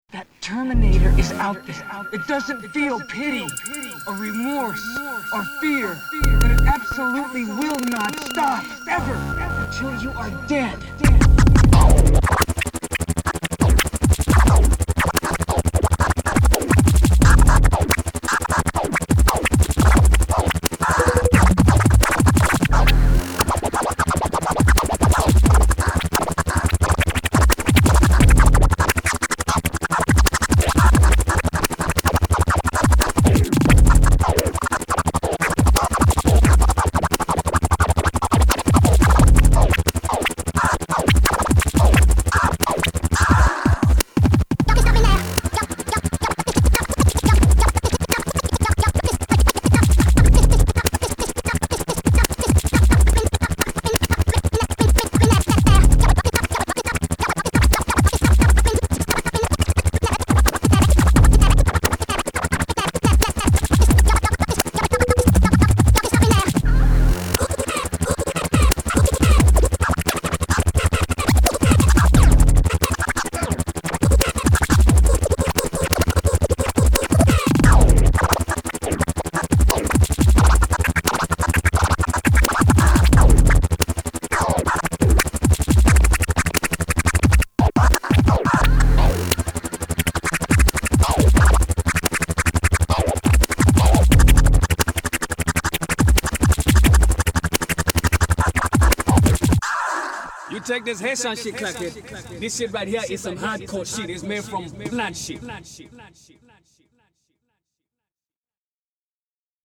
all cuts and scratches